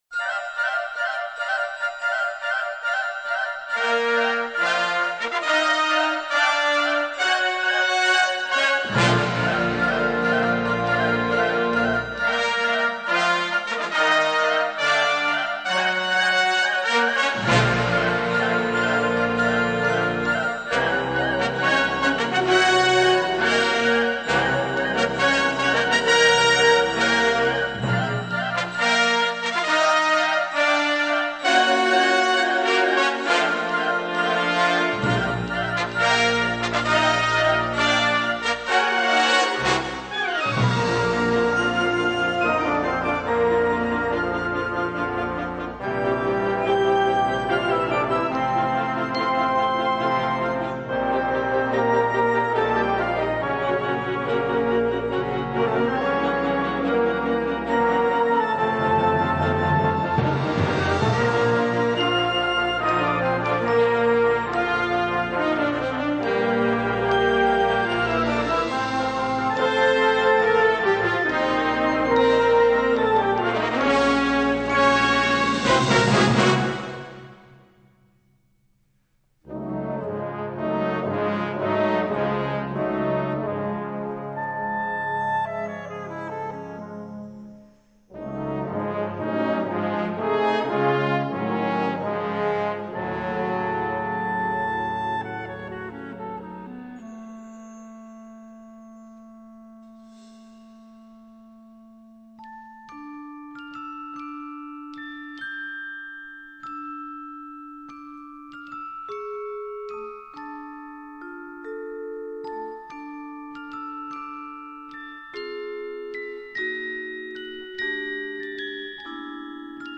23 x 30,5 cm Besetzung: Blasorchester Tonprobe